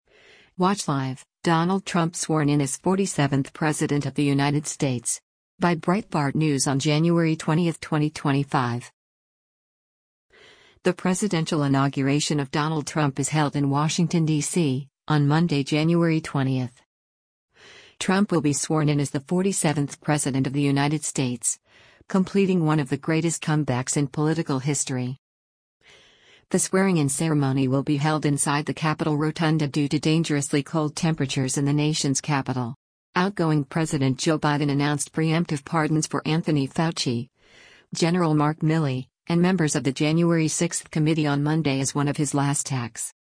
The presidential inauguration of Donald Trump is held in Washington, DC, on Monday, January 20.
The swearing in ceremony will be held inside the Capitol rotunda due to dangerously cold temperatures in the nation’s capital.